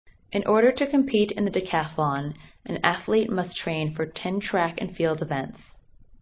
Location: USA